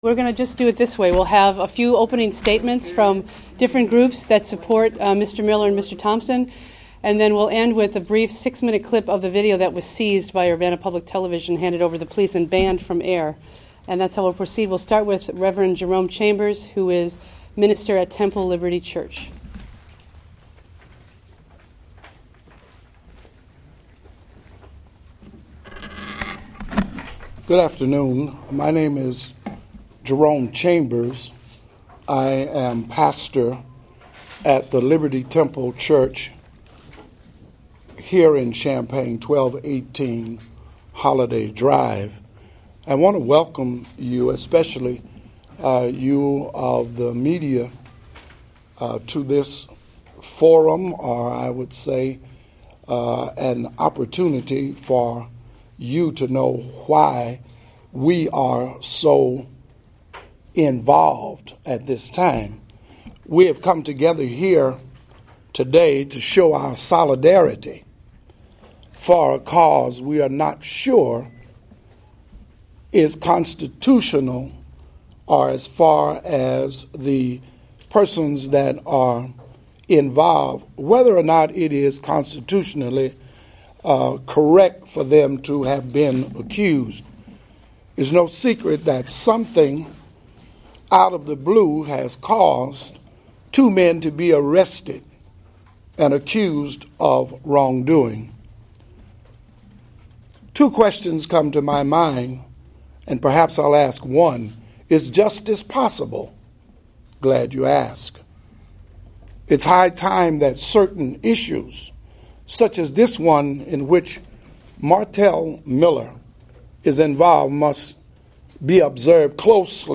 Audio from Sept. 10 Press Conference